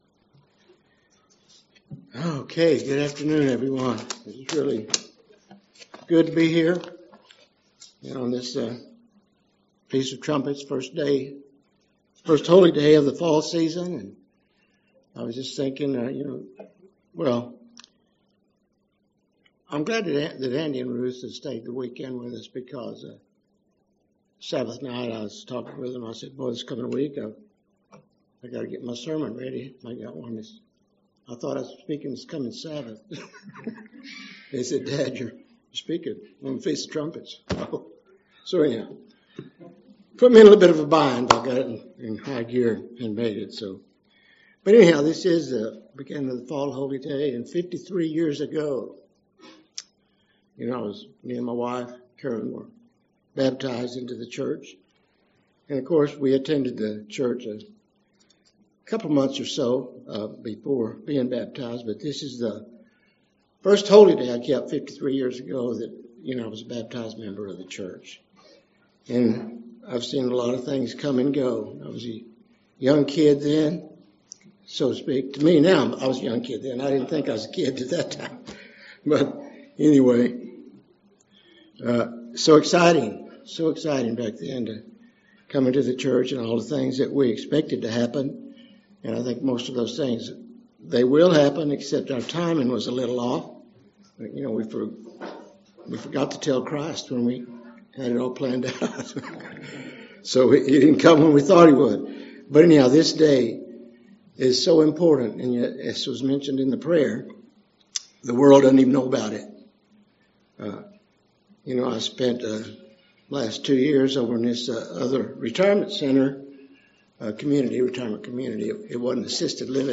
Holy Day Services Studying the bible?
Given in Lubbock, TX